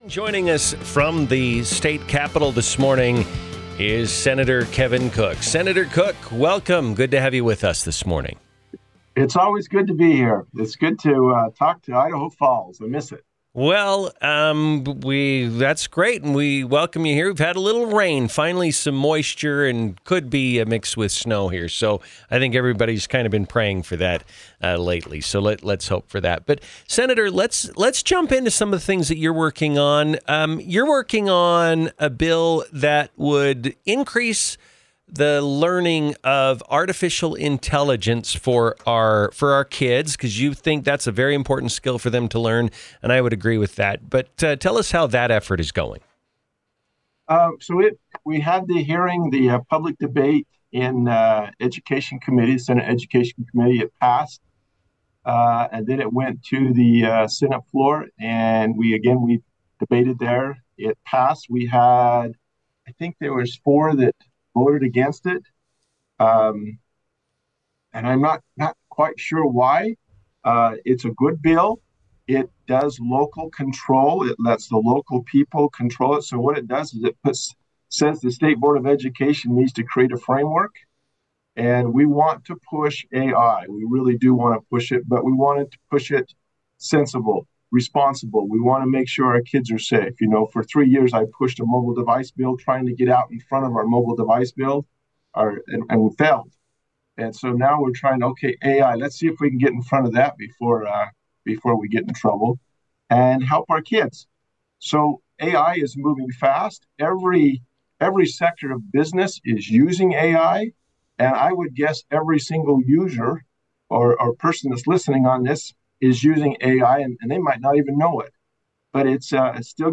INTERVIEW: Senator Kevin Cook on Fiscal and Educational Priorities - Newstalk 107.9